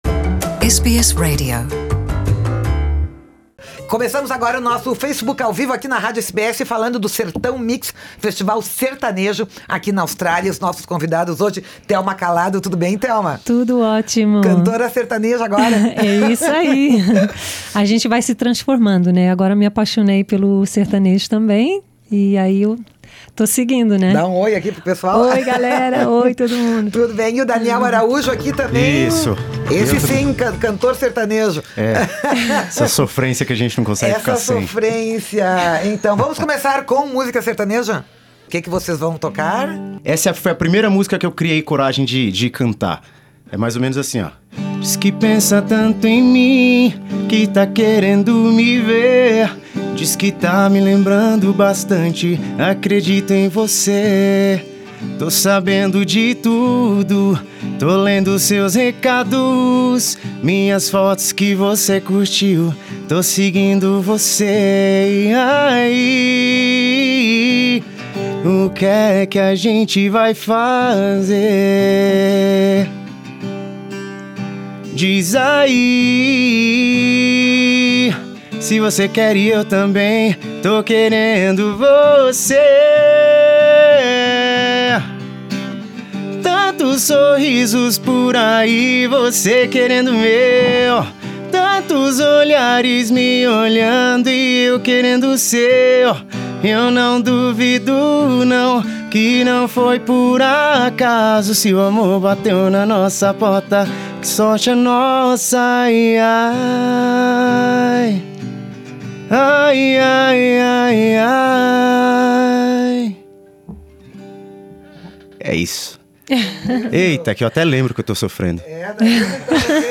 Nessa entrevista à SBS Portuguese